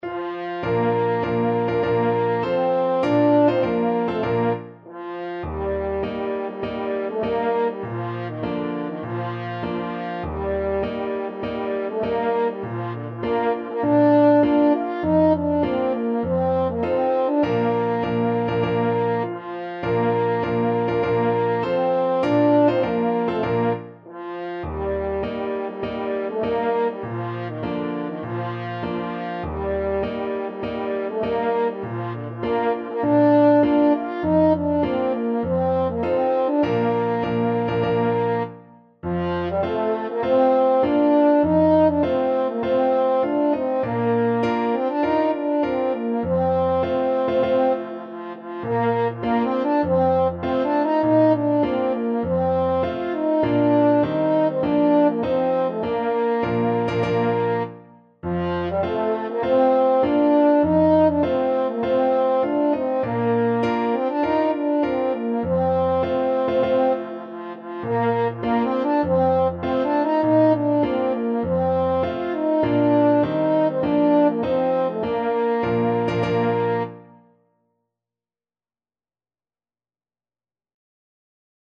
French Horn
Bb major (Sounding Pitch) F major (French Horn in F) (View more Bb major Music for French Horn )
4/4 (View more 4/4 Music)
Classical (View more Classical French Horn Music)